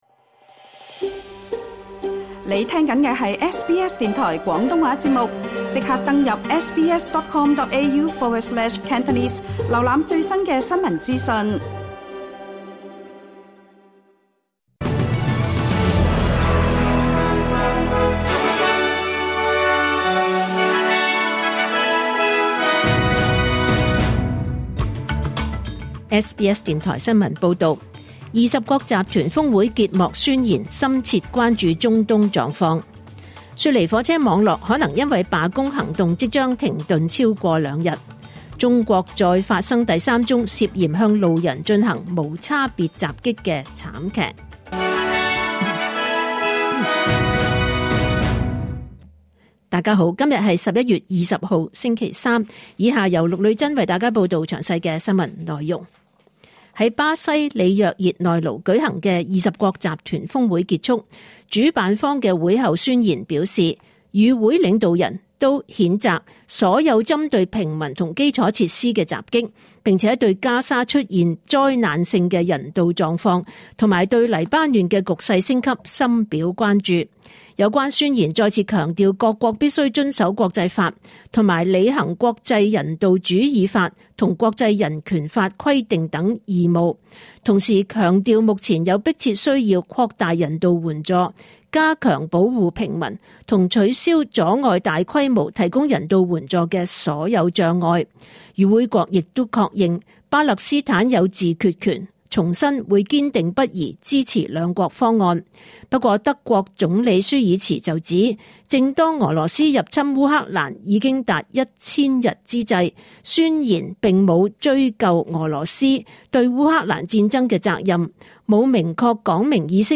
2024 年 11 月 20 日 SBS 廣東話節目詳盡早晨新聞報道。